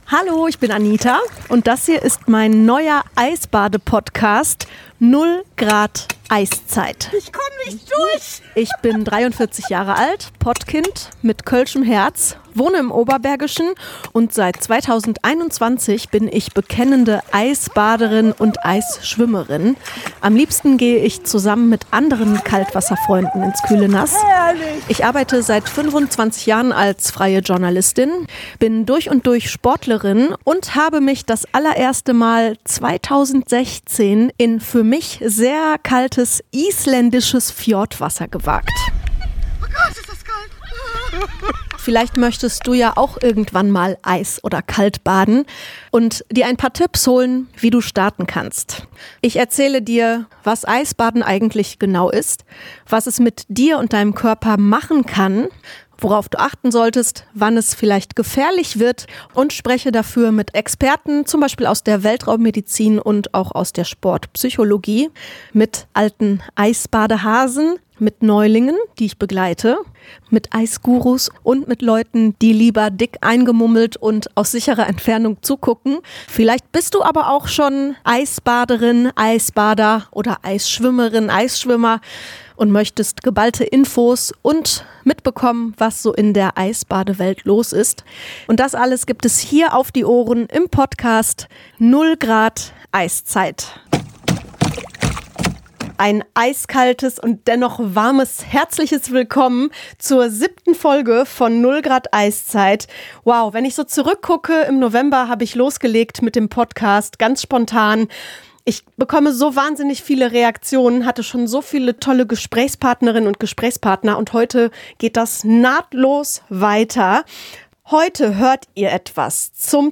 Ich habe die Ortsgruppe Wiehl bei einer Teamübung begleitet.